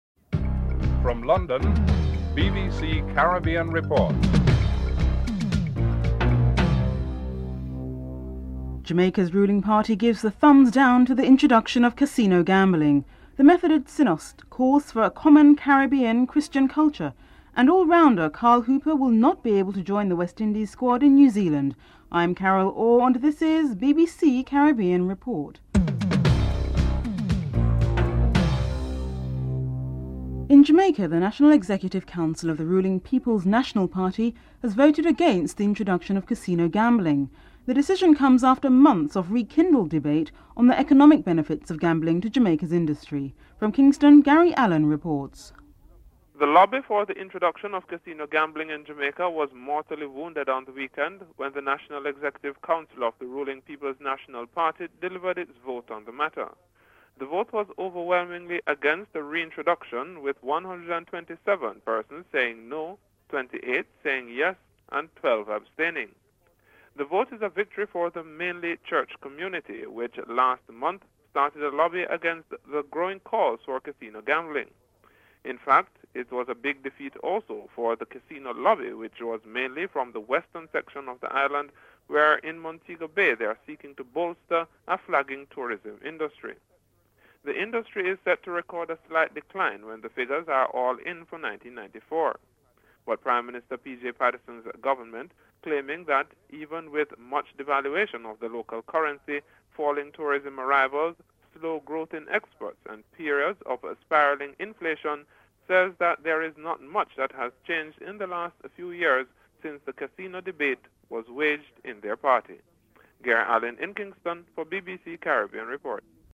2. Report on Jamaica's decision against the introduction of casino gambling (00:30-01:55)
10. Interview with Emanuel Steward on why he decided to work with Lewis and how he plans to improve him (12:44-14:41)